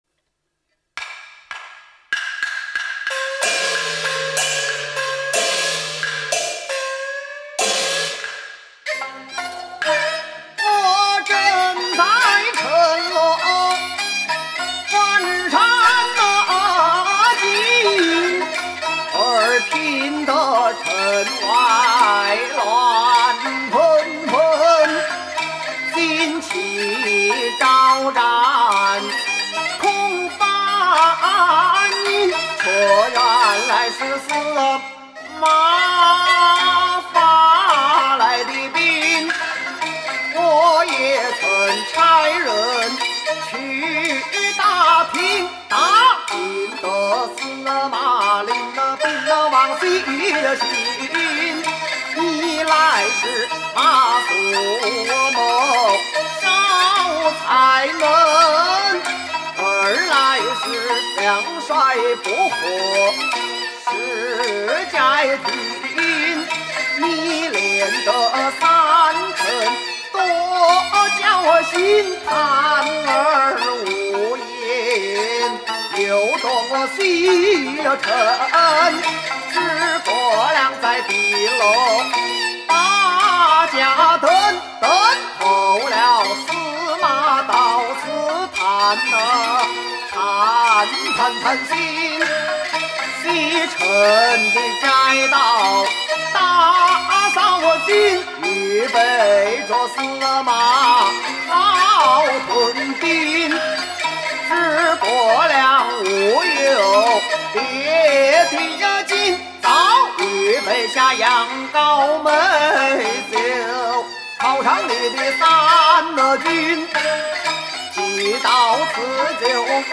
司鼓
CD壹 京剧伴奏音乐（老生）
试听曲 08 示唱与伴奏